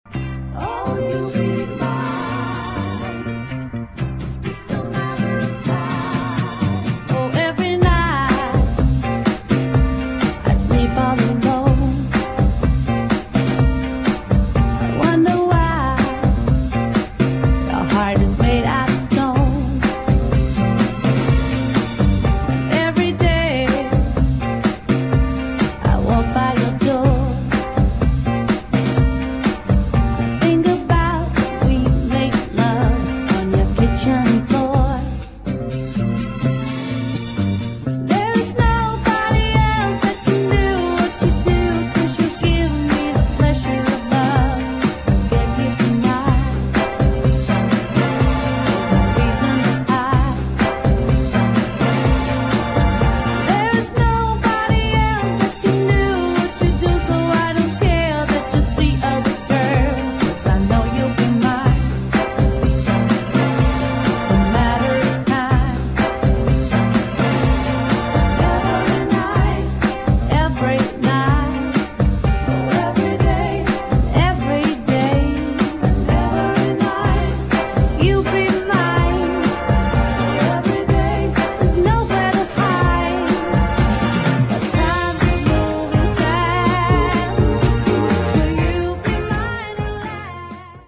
[SOUL / FUNK / JAZZ ]